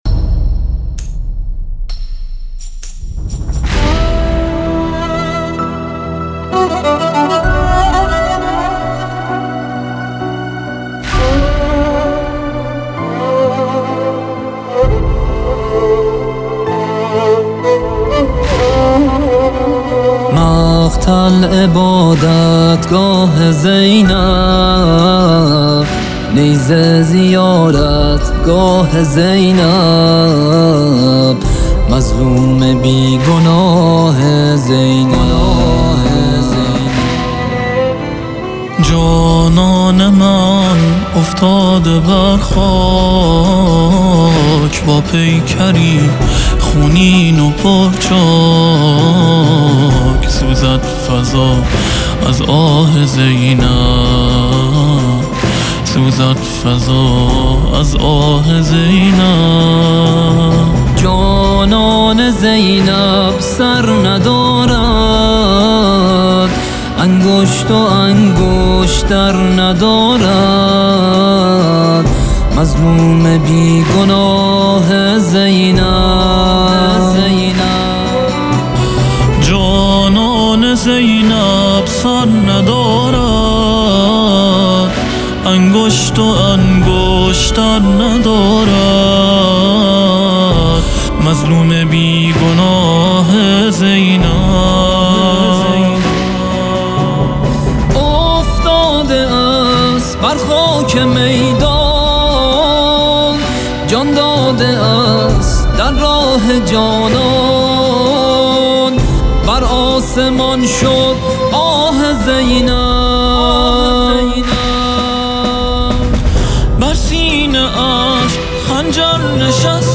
مقتل